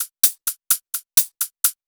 VTDS2 Song Kit 11 Male Out Of My Mind Closed Hihat.wav